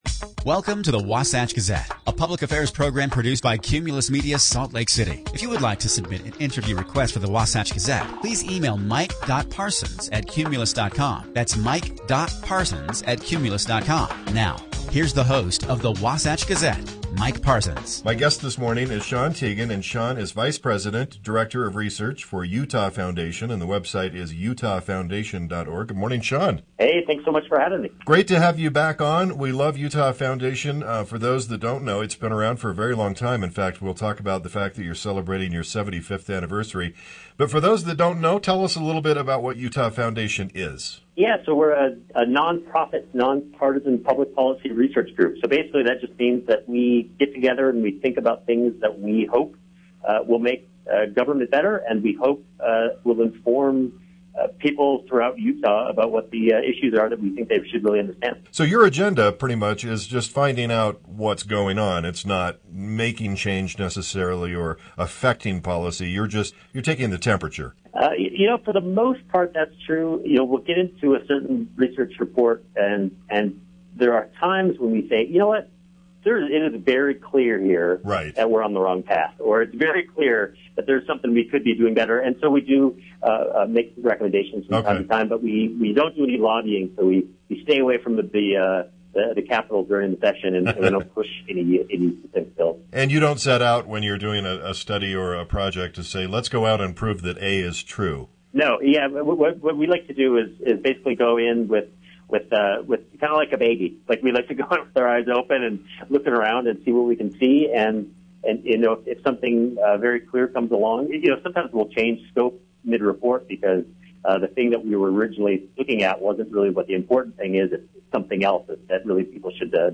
The conversation was part of the Wasatch Gazette that plays on Sundays on: